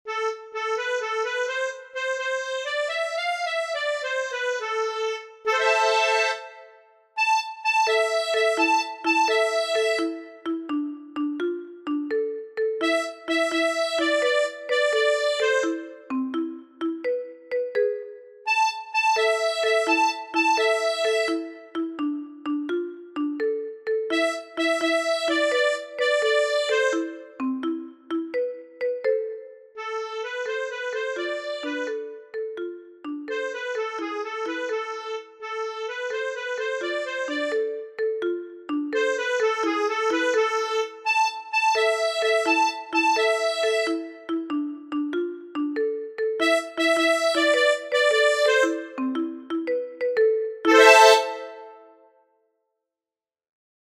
The music is rapid and uses repetitive phrases in changing intensity.
Here you have got the sound file with the silent bars.